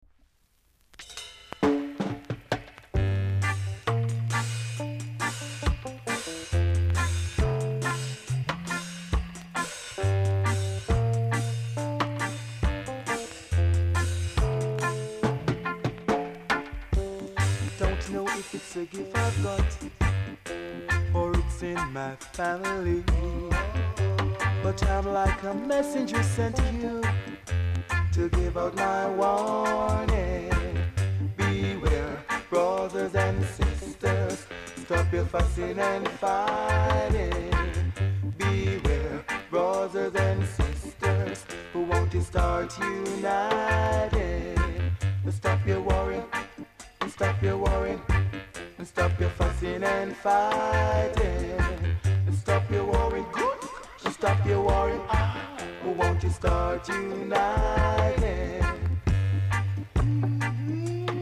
※単発でパチノイズが所々あります。最後のほうでややプチパチします。